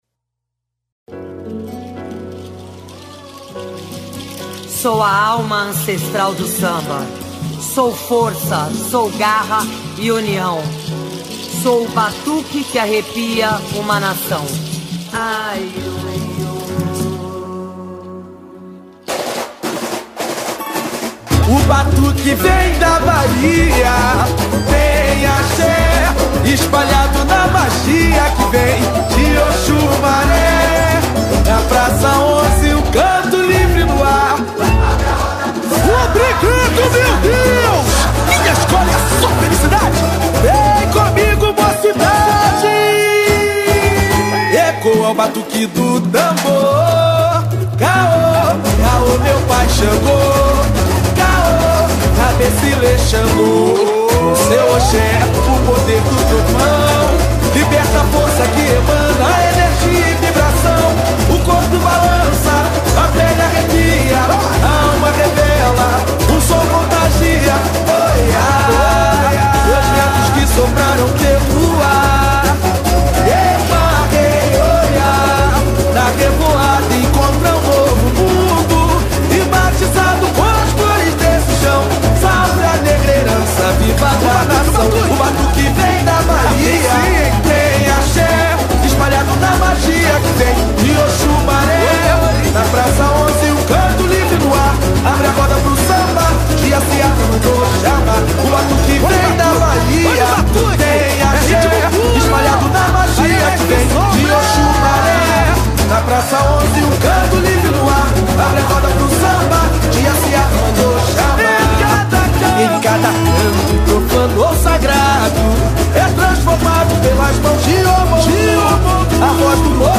Puxador: